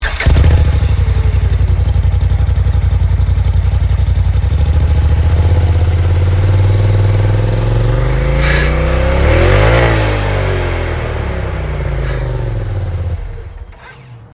POLARIS RZR XPT 1000 STOCK EXHAUST
Stock_RZR.mp3